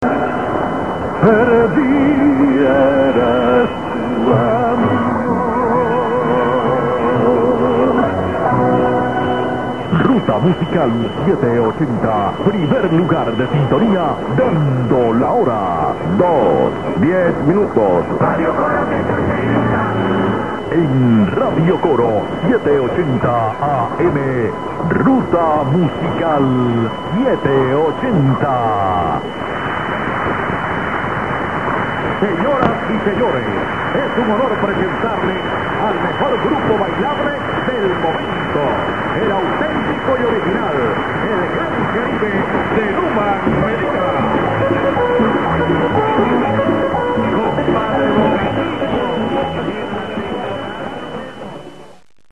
Here are some loggings of MW/SW-stations heard in Paimio (not all stations listed here)